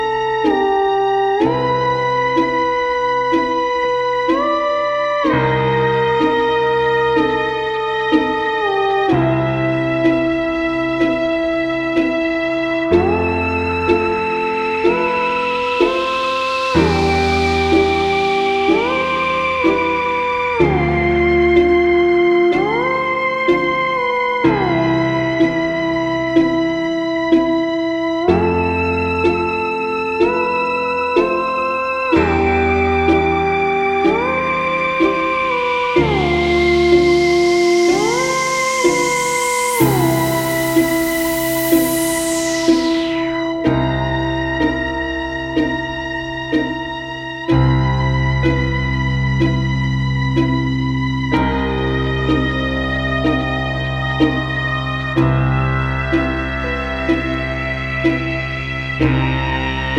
ジャンル(スタイル) NU DISCO / JAZZ FUNK / BALEARICA